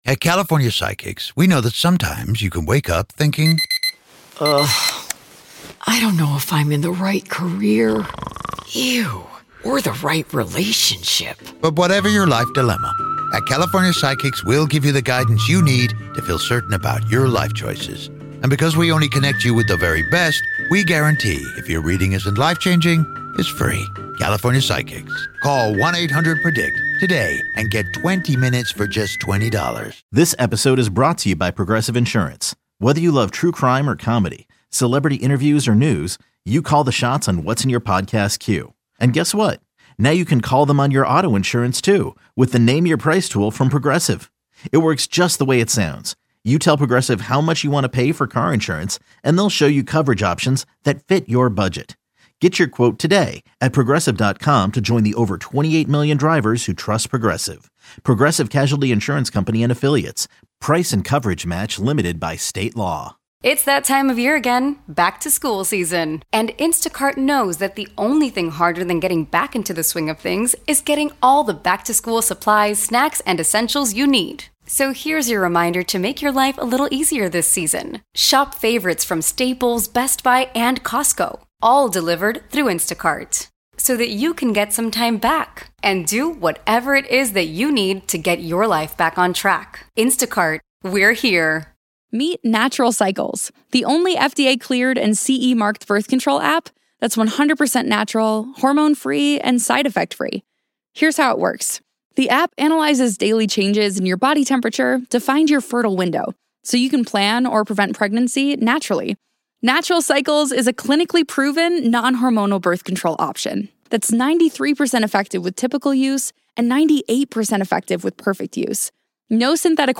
Anthony Carrigan In-Studio.